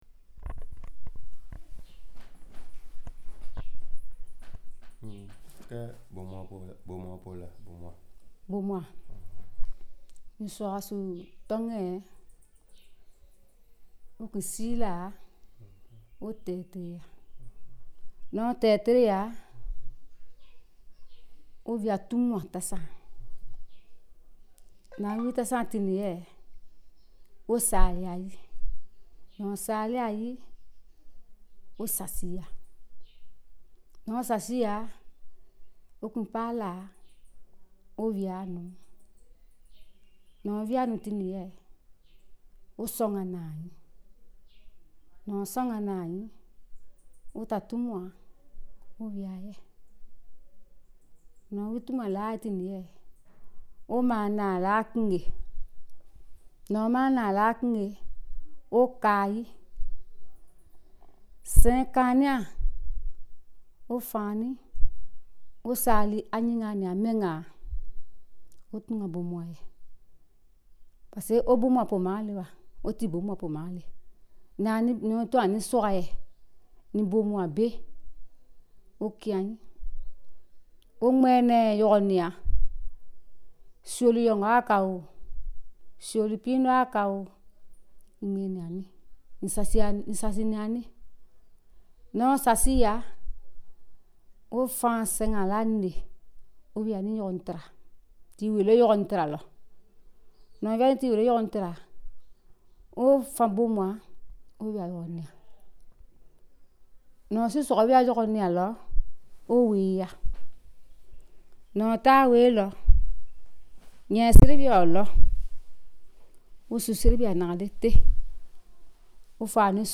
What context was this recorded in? • Audiofiles were recorded in the field with a Tascam portable digital recorder. • fieldwork in Bonosso village, Côte d’Ivoire, chiefly 2016-19.